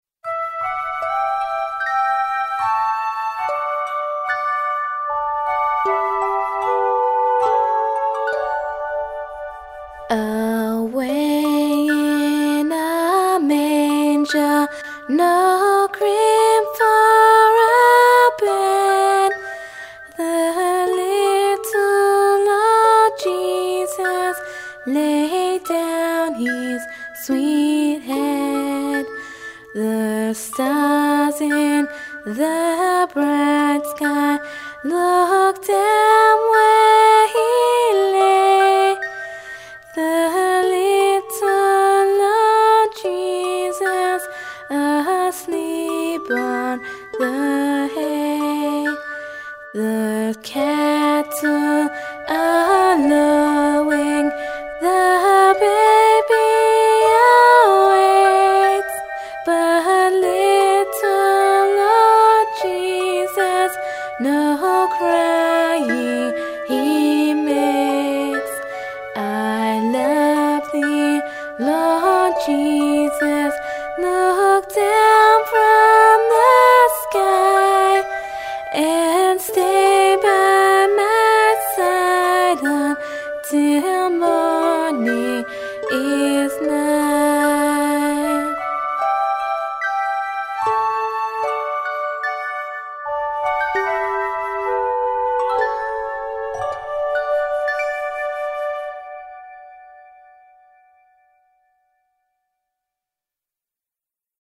Genre - Christmas carols
Choir/Band audio sample